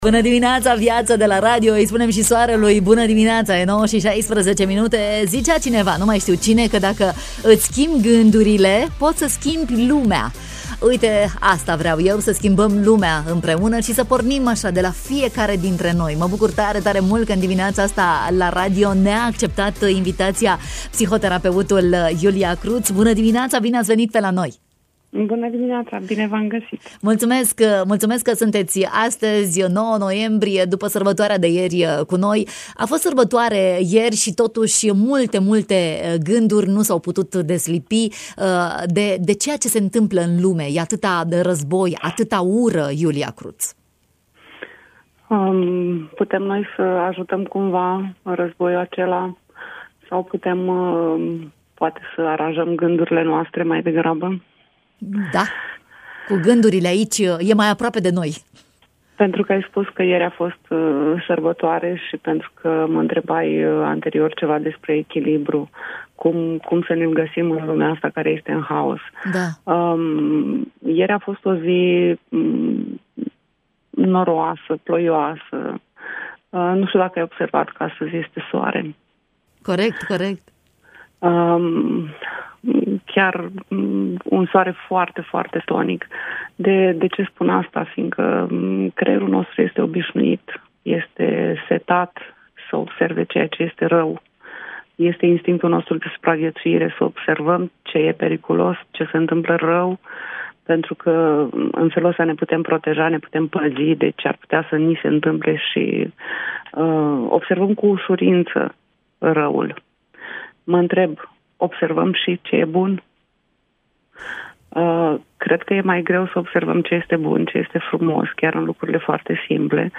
în matinal, despre liniile fine ale stării de bine